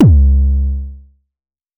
Index of /musicradar/essential-drumkit-samples/Vermona DRM1 Kit
Vermona Kick 02.wav